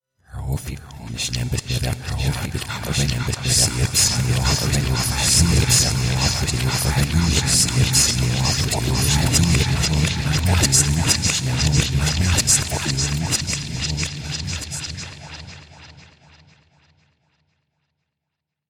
Звуки реверса
Звук мужского голоса в обратном воспроизведении